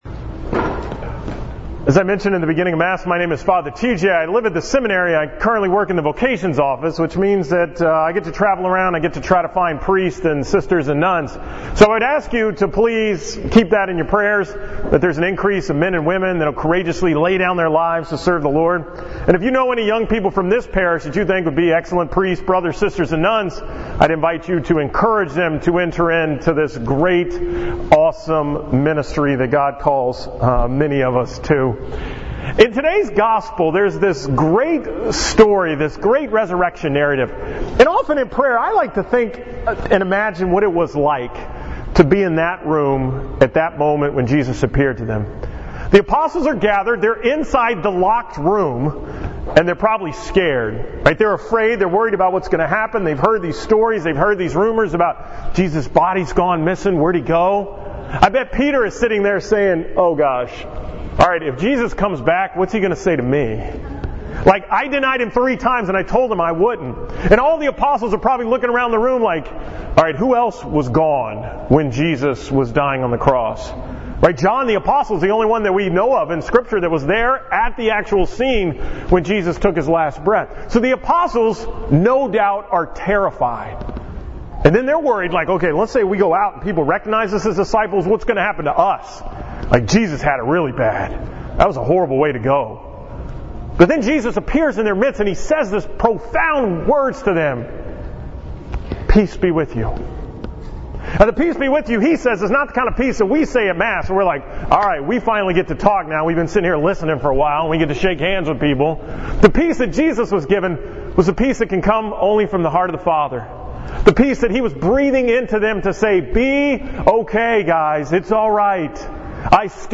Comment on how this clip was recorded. From the 2nd Sunday of Easter at Sacred Heart in Manvel on April 23, 2017